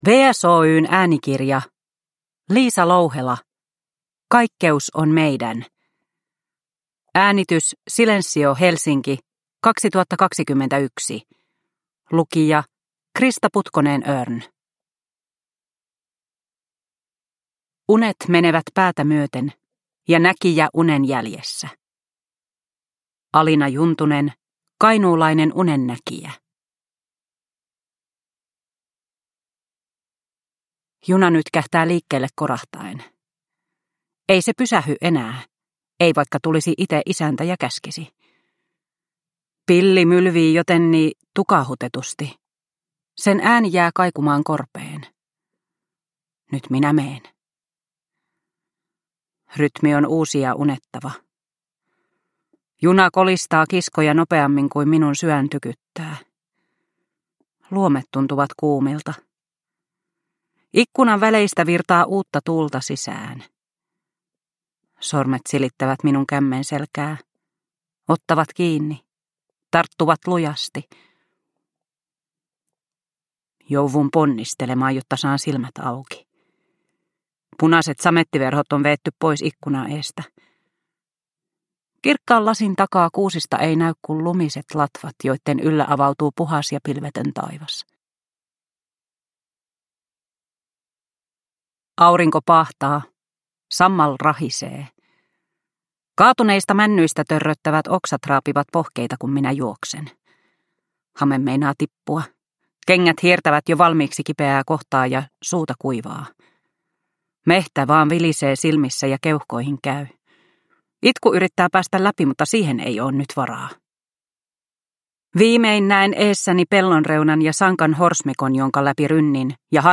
Kaikkeus on meidän – Ljudbok – Laddas ner